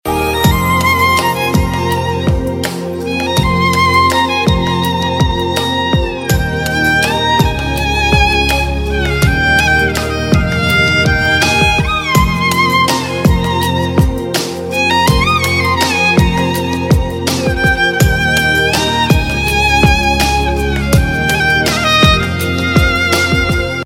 Instrumental MP3 Ringtones